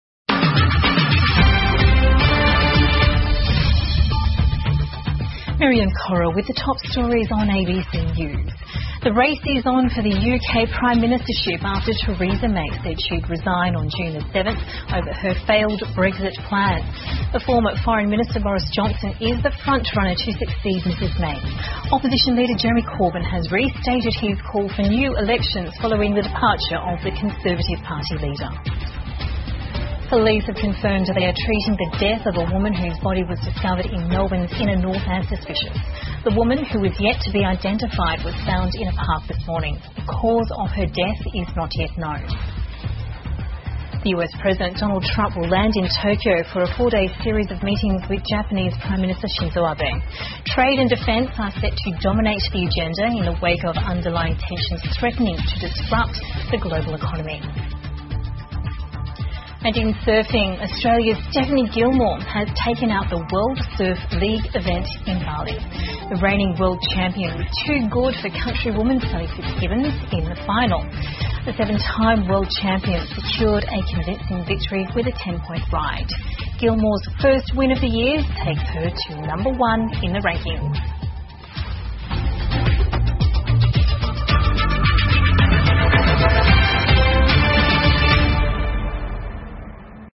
澳洲新闻 (ABC新闻快递) 英国首相梅宣布辞职 特朗普访问日本 听力文件下载—在线英语听力室